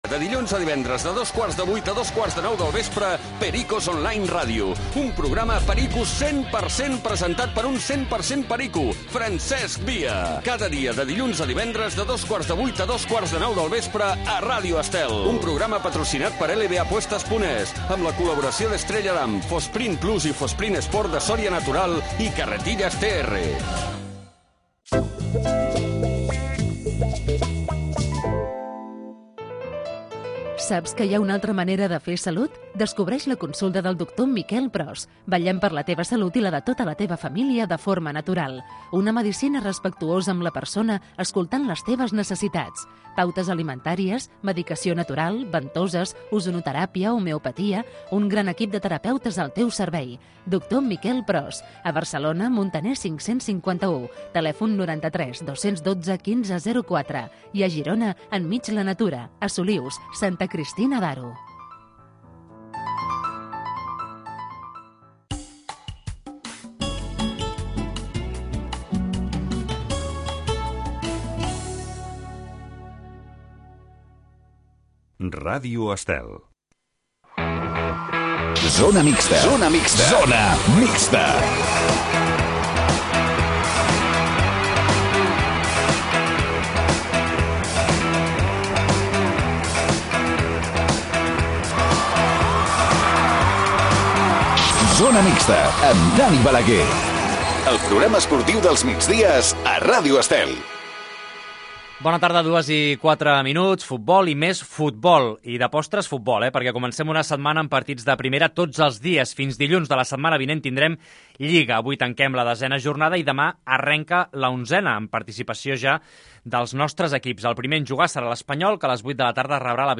Zona mixta. Programa diari dedicat al món de l'esport. Entrevistes amb els protagonistes de l'actualitat poliesportiva.